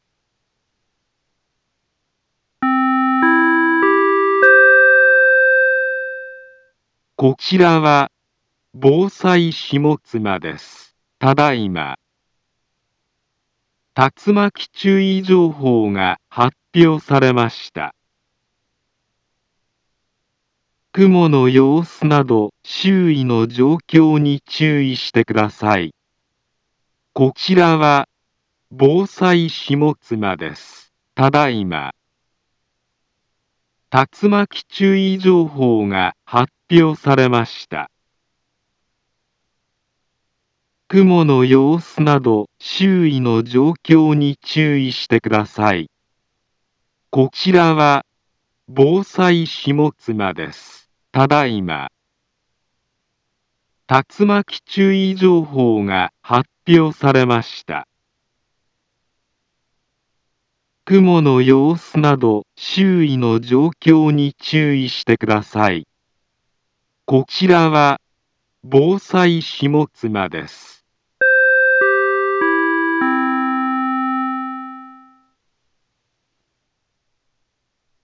Back Home Ｊアラート情報 音声放送 再生 災害情報 カテゴリ：J-ALERT 登録日時：2023-09-20 16:34:59 インフォメーション：茨城県南部は、竜巻などの激しい突風が発生しやすい気象状況になっています。